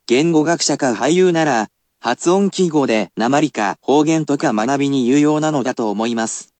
[basic polite speech]